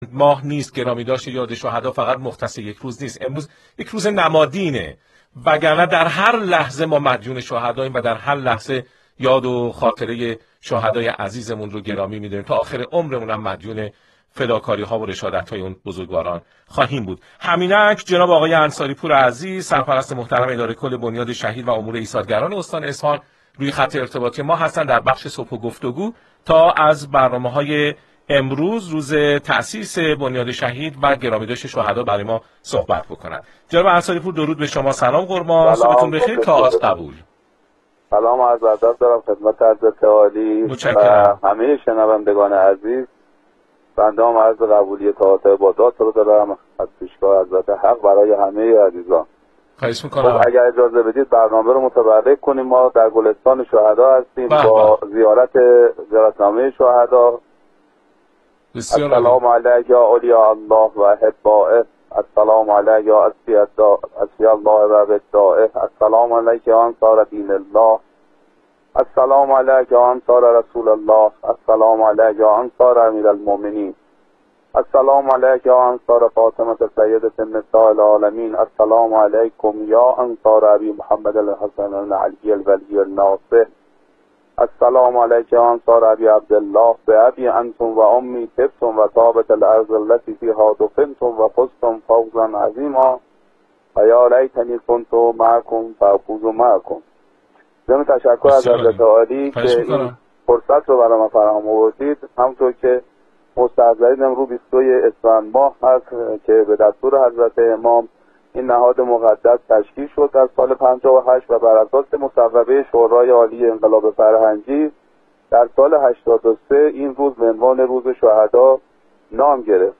به مناسبت ۲۲ اسفند «روز بزرگداشت شهدا»، انصاری‌پور سرپرست اداره کل بنیاد شهید و امور ایثارگران استان اصفهان در مصاحبه‌ای با رادیو اصفهان یاد و خاطره شهدای والامقام را گرامی داشت.